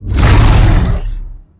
Sfx_creature_iceworm_flinch_01.ogg